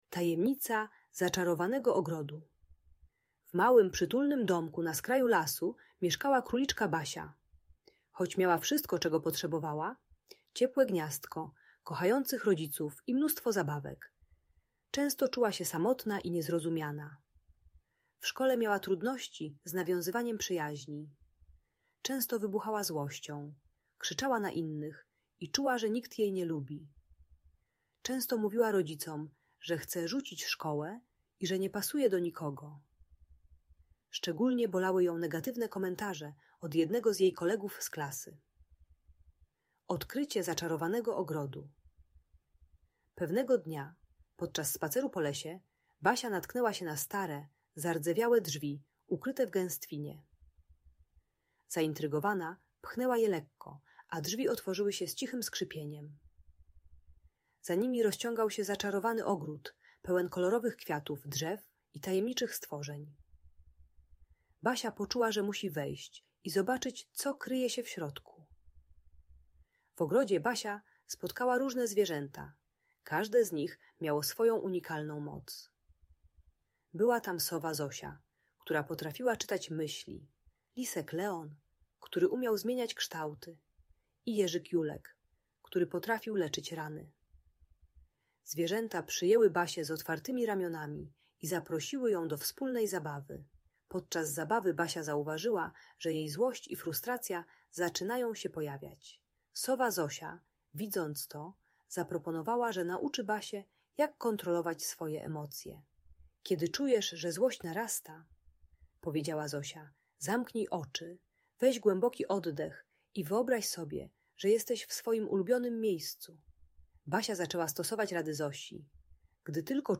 Tajemnica Zaczarowanego Ogrodu - Audiobajka dla dzieci